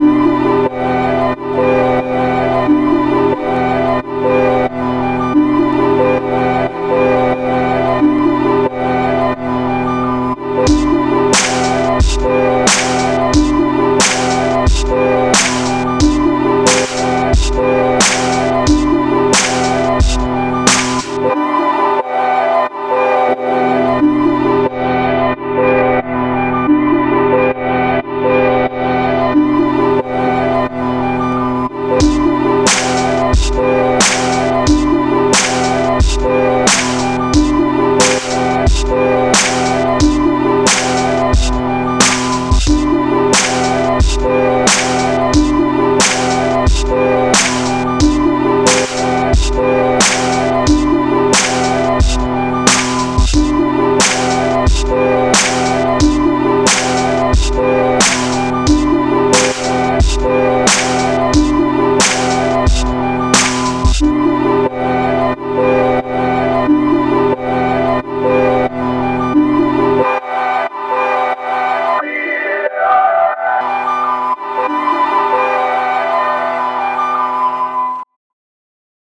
The Big Elektronauts Hip-Hop Thread: production tips, sharing our music, feedback and inspiration
Didn’t have the dedication to make more time consuming tracks the last weeks, but continue to make a few beats every evening on the couch, with koala on the ipad. Flipping a sample just has something very relaxing. Like to share some sketches, nothing seriously finished. Bass is still missing here and there, these were just quick jams with koala.